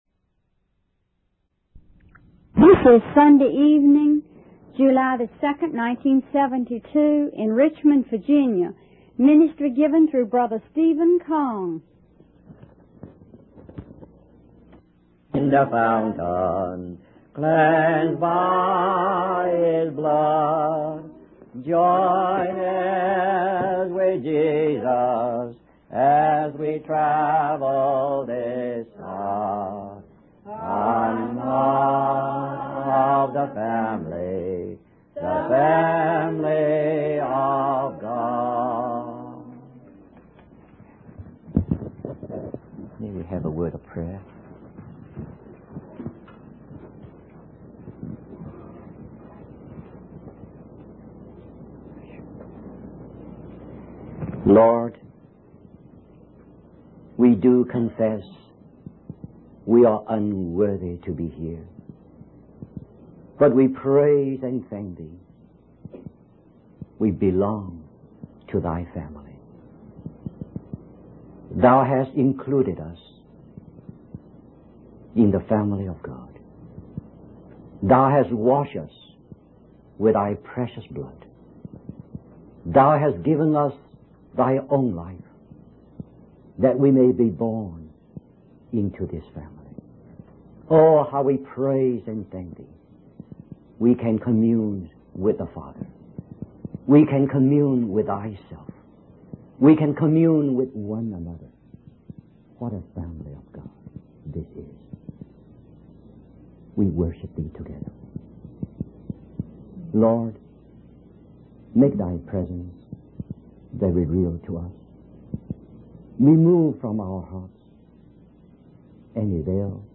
In this sermon, the speaker emphasizes the importance of learning Christ before being able to effectively present Him to others. He explains that the measure of our gospel is based on the measure of Christ we know. The speaker also highlights the need for believers to go through training and hardships in order to become good soldiers of the Lord Jesus.